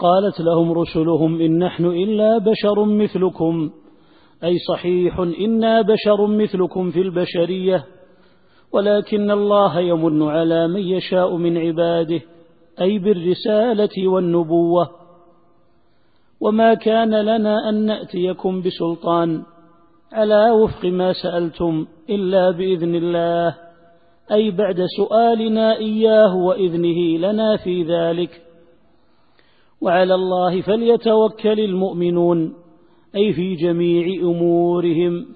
التفسير الصوتي [إبراهيم / 11]